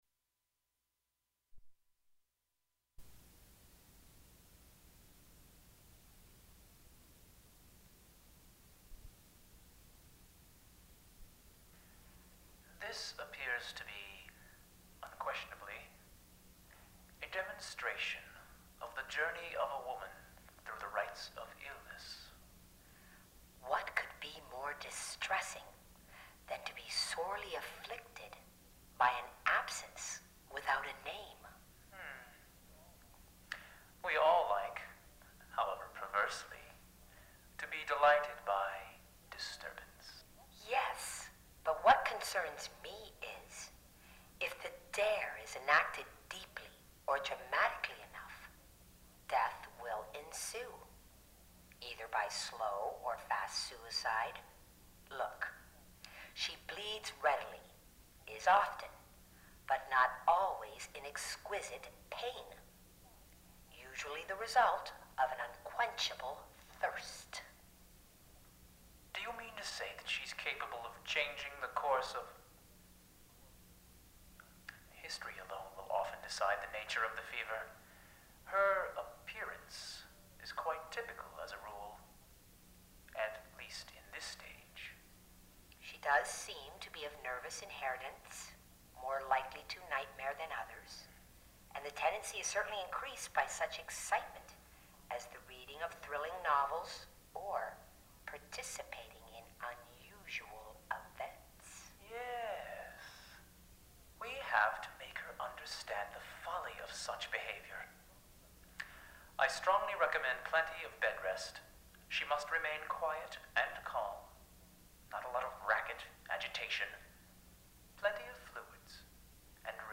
Gorgeous Fever, 1994, Chicago, Randolph Street Gallery, multimedia performance, "Doctor's Report" audio for performance
multimedia performance
Cassette